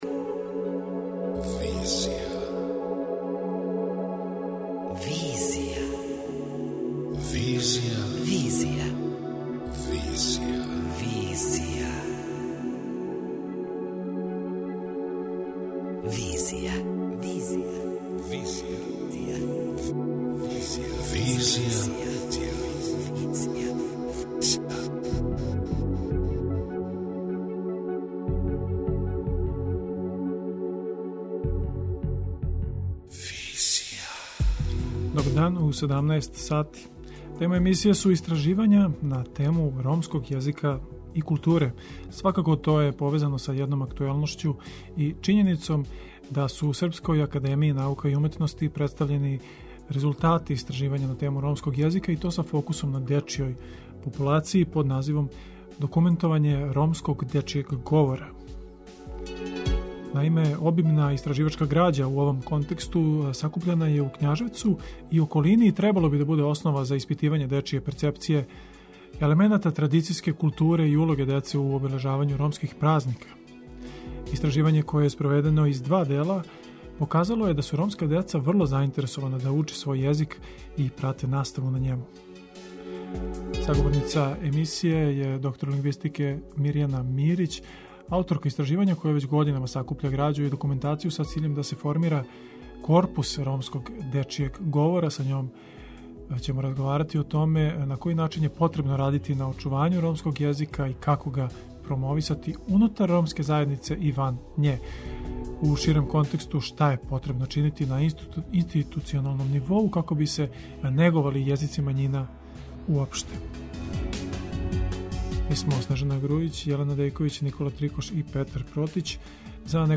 преузми : 27.27 MB Визија Autor: Београд 202 Социо-културолошки магазин, који прати савремене друштвене феномене.